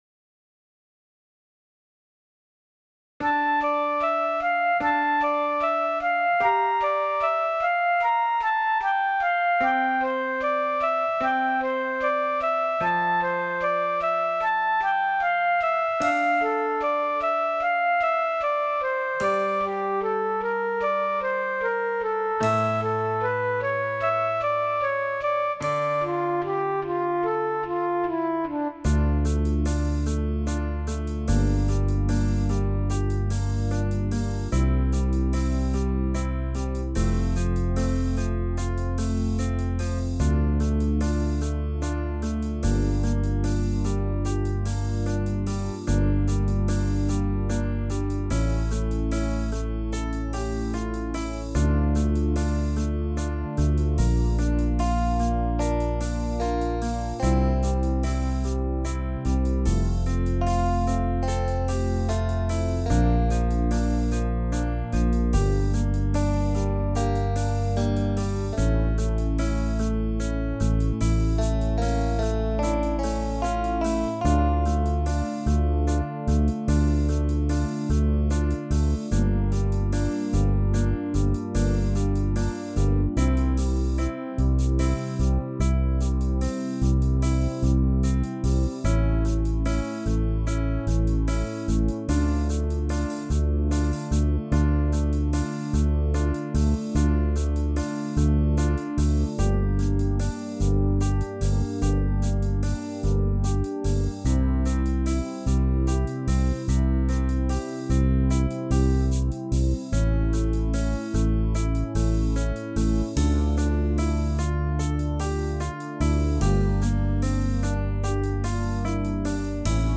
mp3 (минус)